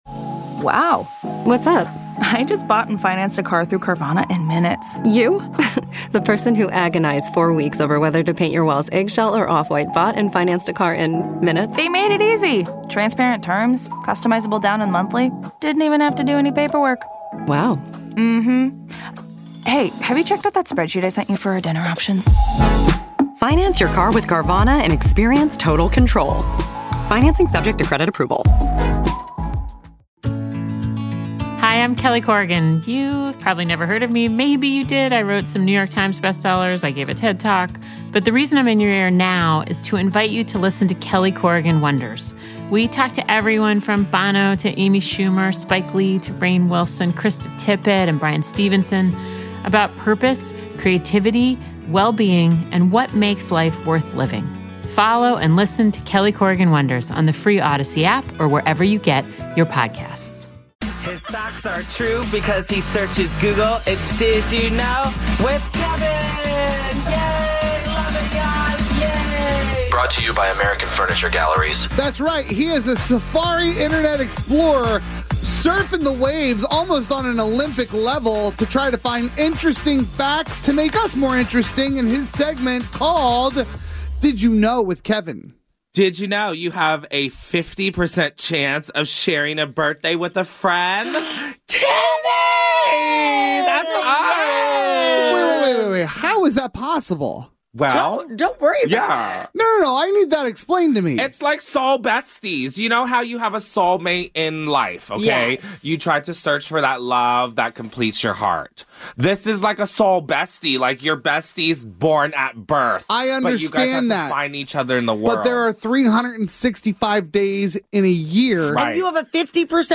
Comedy Entercom Communications Corp Wake Did You Know